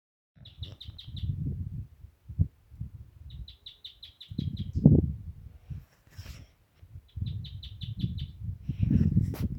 Bandurrita Chaqueña (Tarphonomus certhioides)
Nombre en inglés: Chaco Earthcreeper
Localización detallada: Reserva Natural El Caranday
Condición: Silvestre
Certeza: Observada, Vocalización Grabada
Bandurrita-chaquena_1.mp3